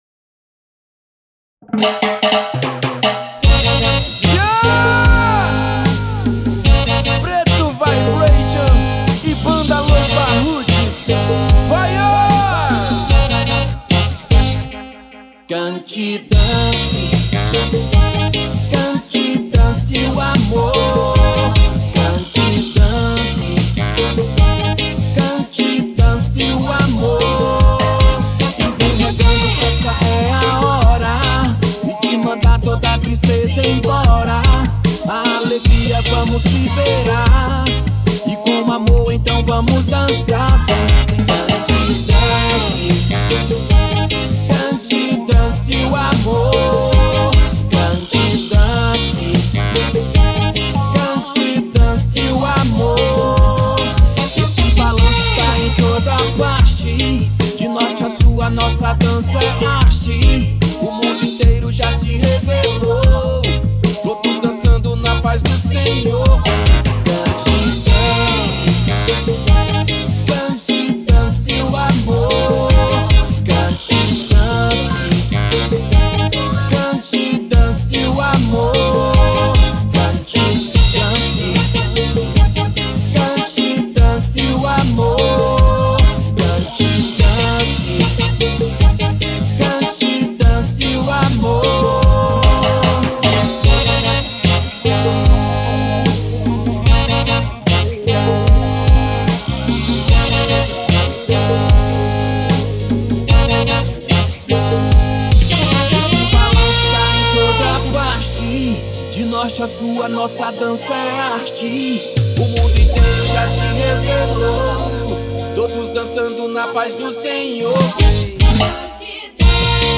EstiloReggae